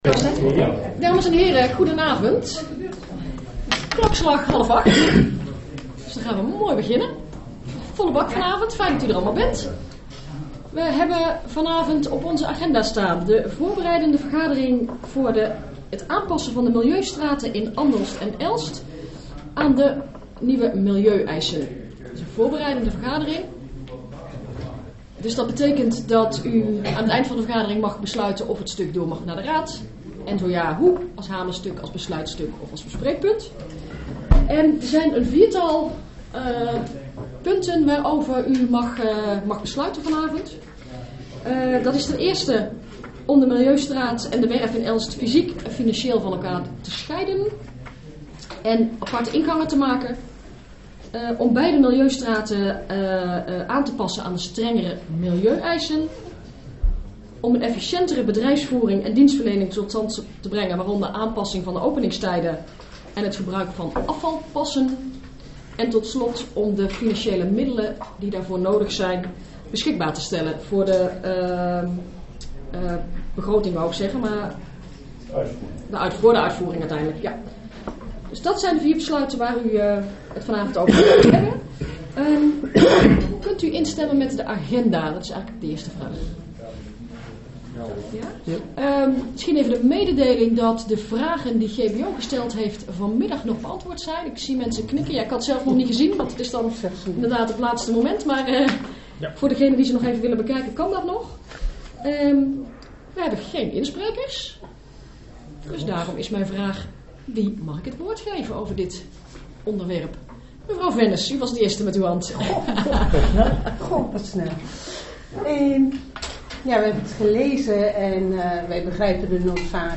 Elster Toren S02, gemeentehuis Elst
Voorbereidende vergadering Aanpassen milieustraten in Andelst en Elst aan milieueisen